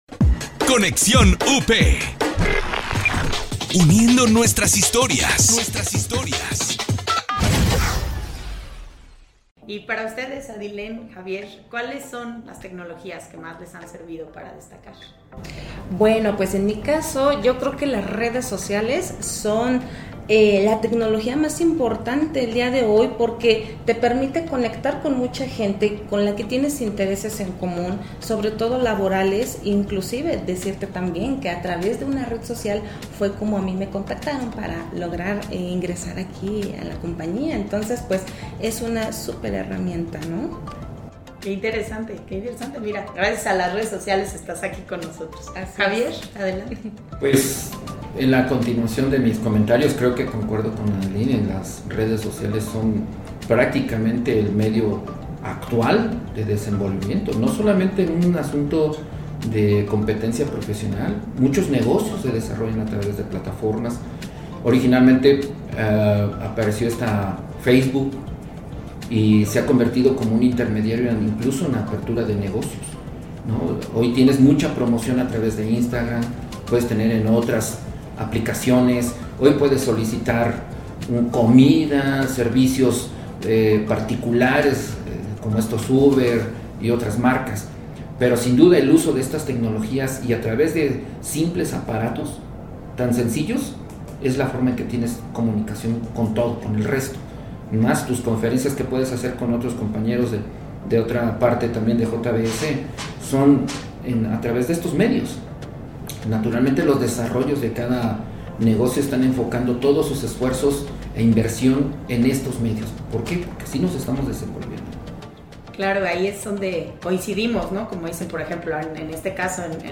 En este episodio reunimos a tres invitados representando a varias generaciones que pertenecen a la Gran Familia Pilgrim's.